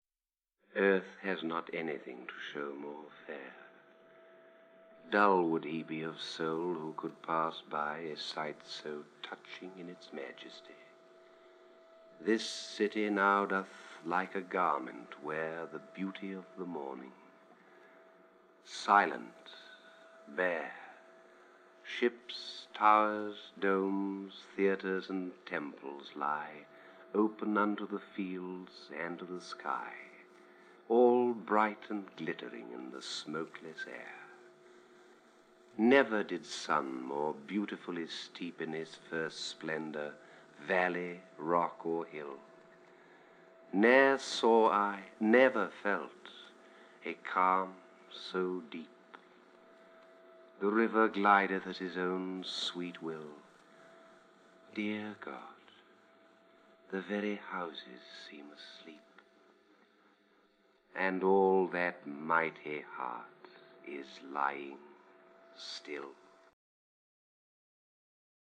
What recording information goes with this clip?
When his health made it difficult for him to pursue acting roles, he began to record favourite poems in his home.